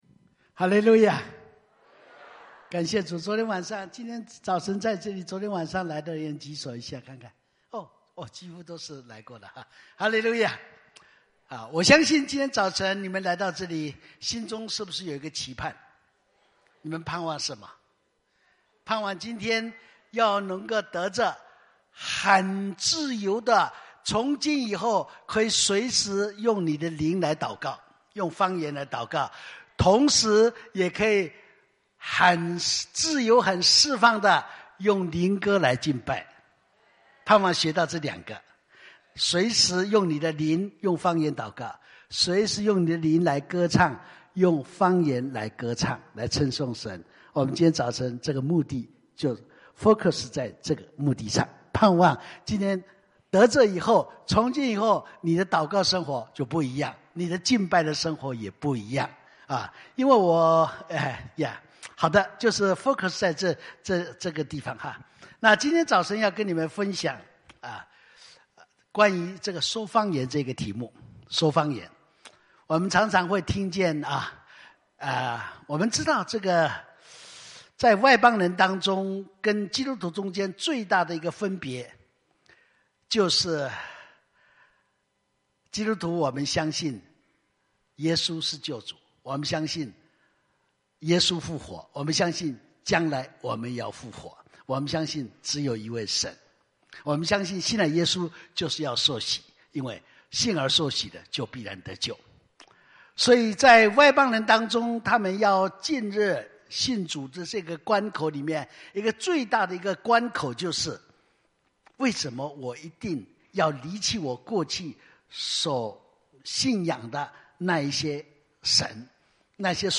圣灵更新特会(二)